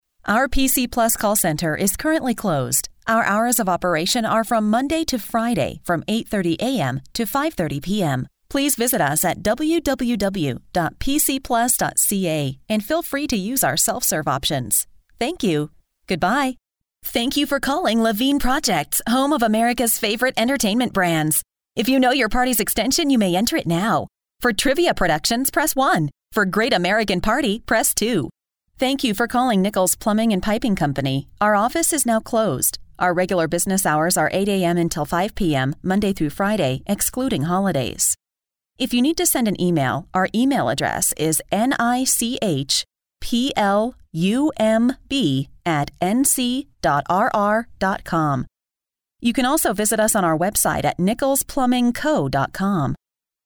American English Voice Over artist
Sprechprobe: Sonstiges (Muttersprache):